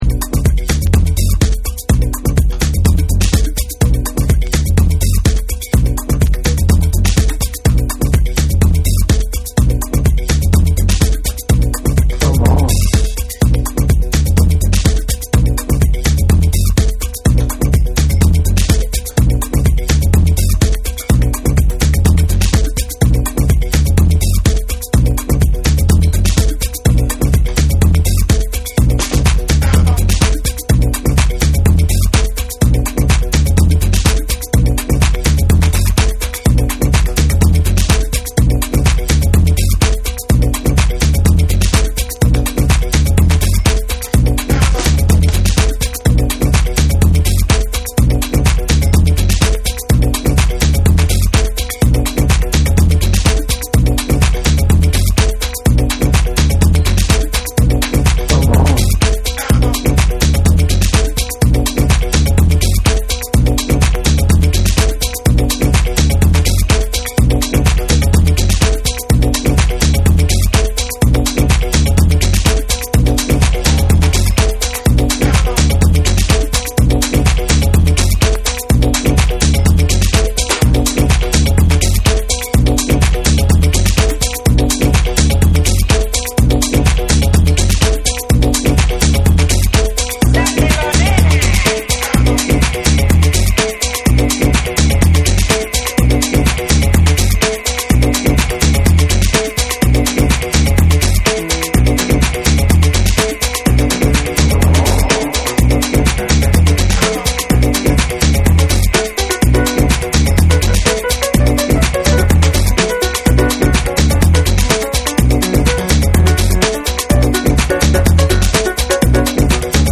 パーカッシヴなリズムが鮮烈に広がっていく
TECHNO & HOUSE / ORGANIC GROOVE